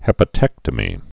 (hĕpə-tĕktə-mē)